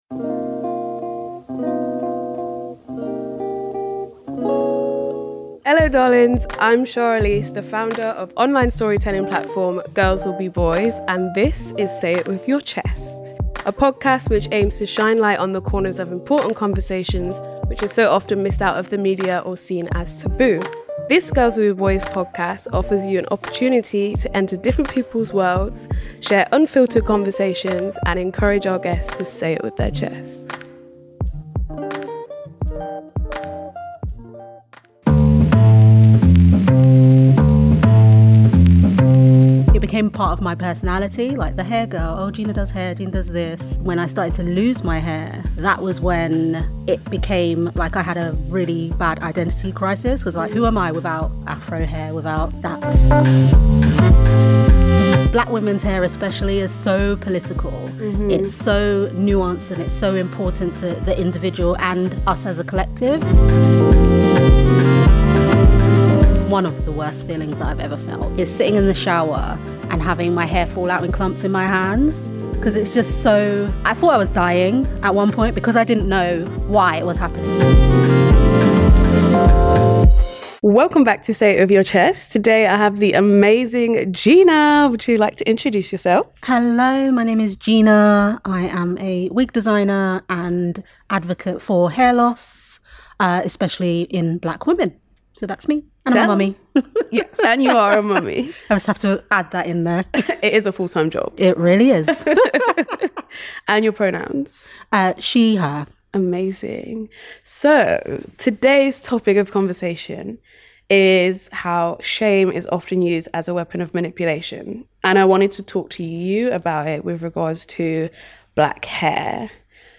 On this weeks episode of Say It With Your Chest I am speaking to wig designer and hair loss advocate